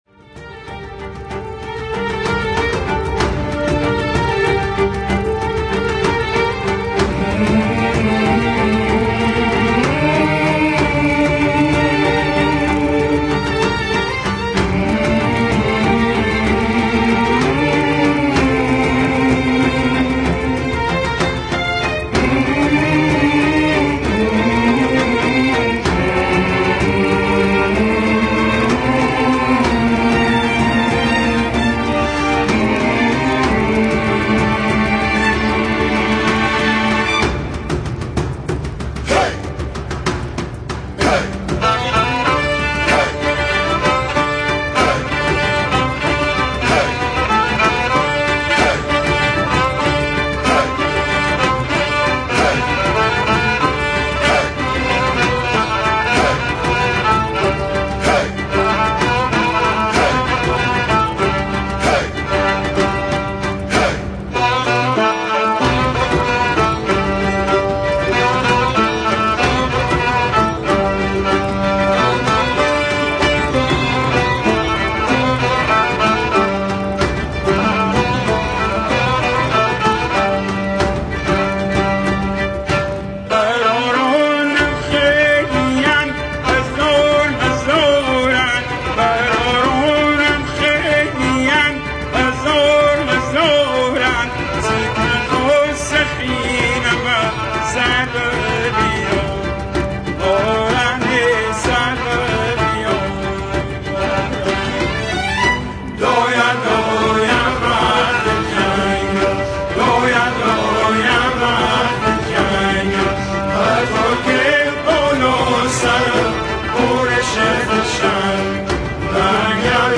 لری